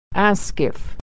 Reading - Robert Frost - Authentic American Pronunciation